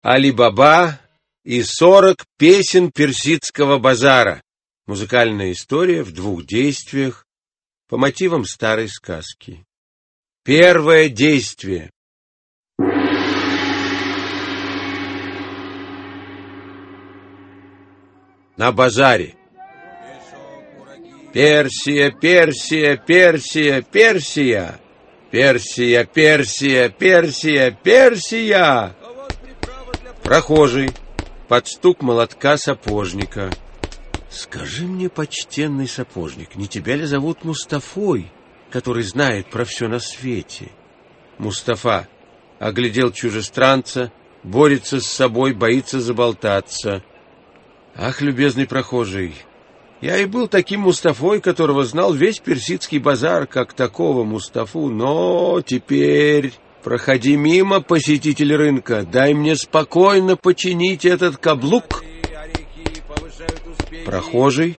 Аудиокнига Али-Баба и сорок разбойников | Библиотека аудиокниг
Aудиокнига Али-Баба и сорок разбойников Автор Вениамин Смехов Читает аудиокнигу Вениамин Смехов.